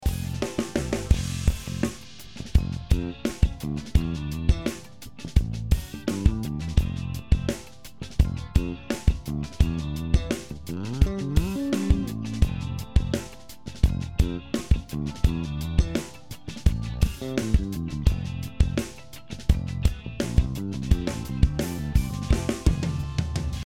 まずはEQとコンプをかけただけの4リズムを聴いてみましょう。